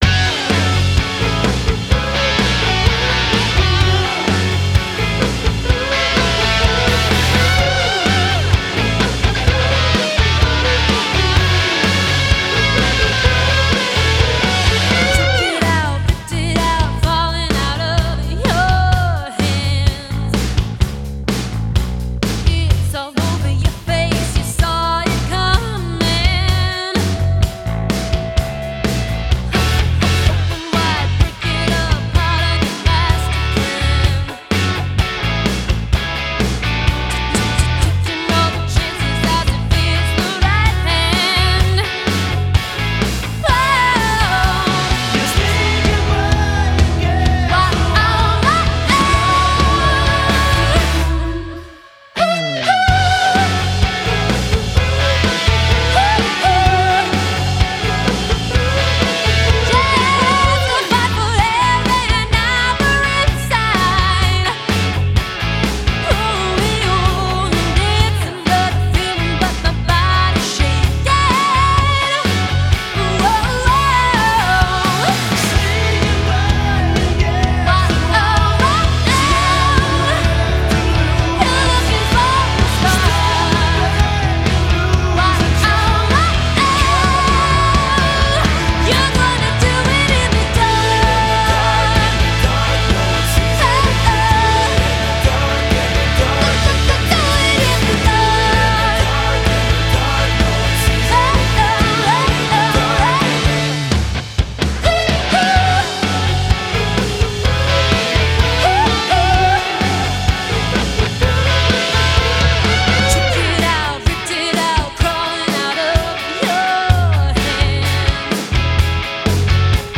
And it does sound crisp.